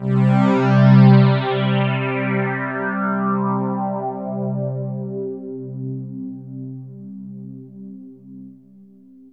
AMBIENT ATMOSPHERES-5 0006.wav